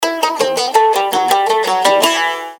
• Качество: 320, Stereo
гитара
без слов
восточные
Гитарка красивая, мелодия звучная